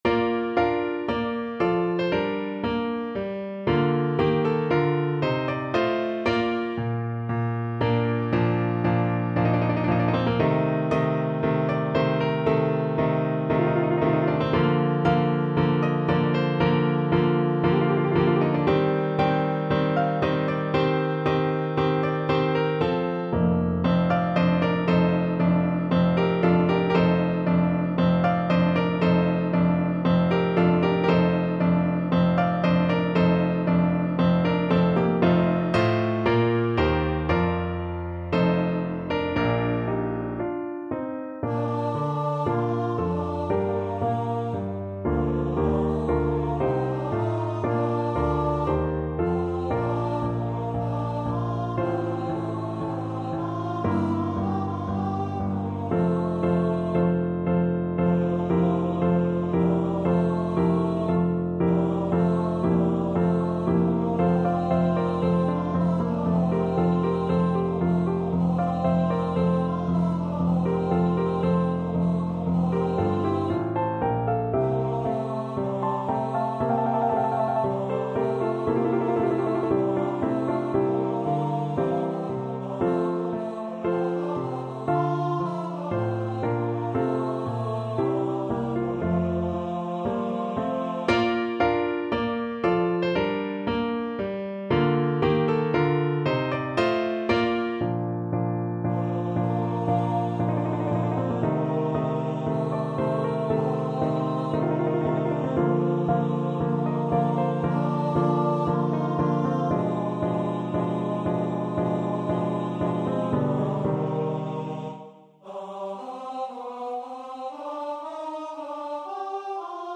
Free Sheet music for Tenor Voice
Tenor Voice
4/4 (View more 4/4 Music)
Bb major (Sounding Pitch) (View more Bb major Music for Tenor Voice )
Andante ( = c.116)
Classical (View more Classical Tenor Voice Music)